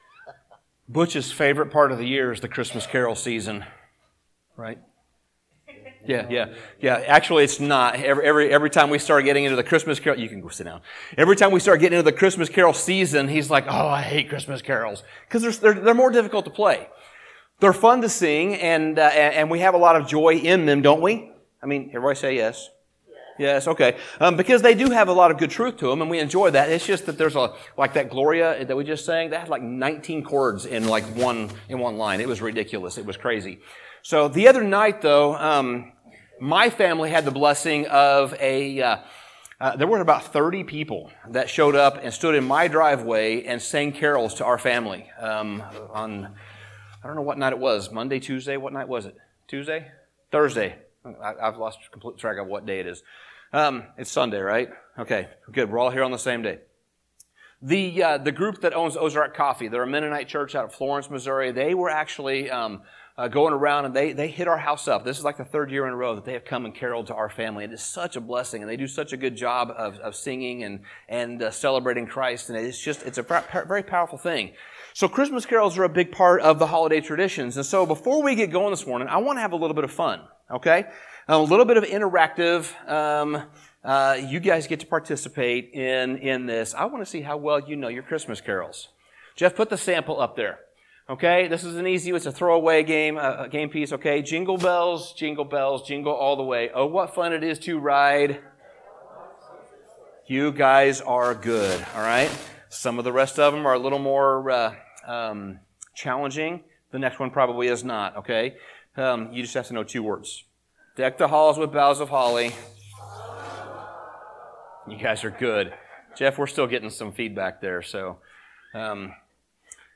Sermon Summary Matthew's nativity account tells of the encounter that Joseph, the earthly father of Jesus, had with an angel.